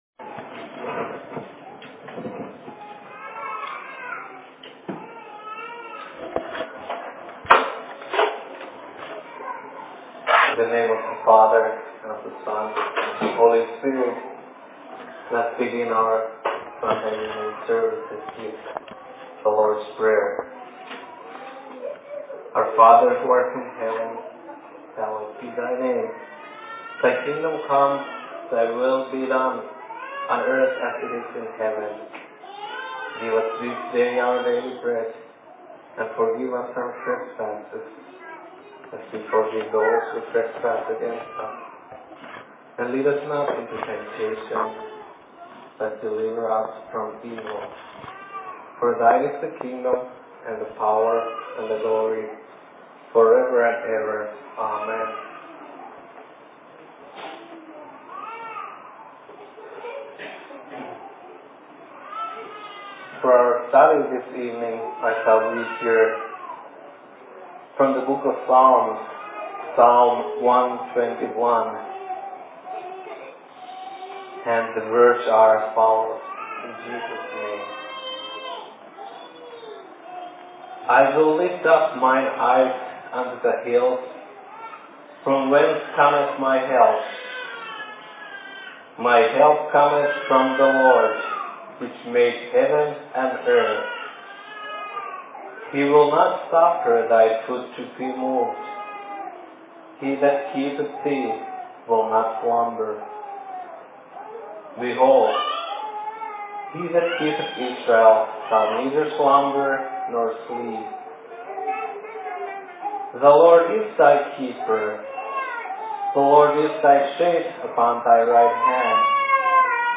Sermon in ElkRiver 19.09.2010
Location: LLC Elk River